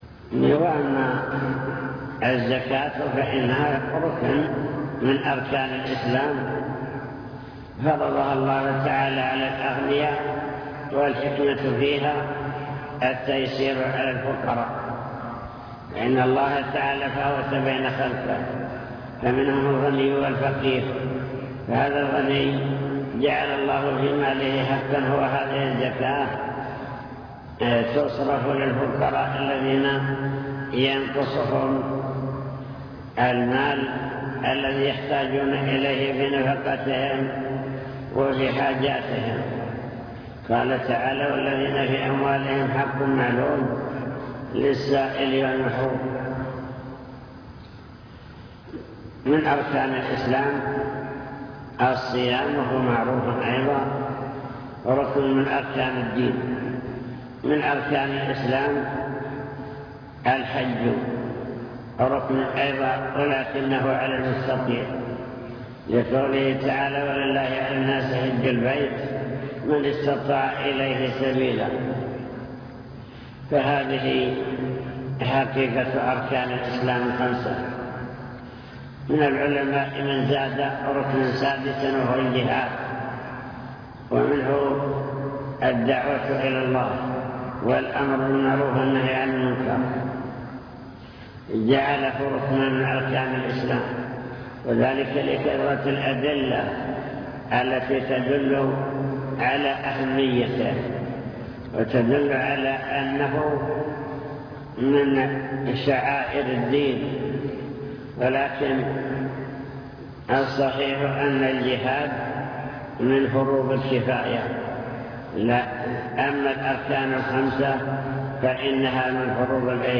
المكتبة الصوتية  تسجيلات - محاضرات ودروس  الإسلام والإيمان والإحسان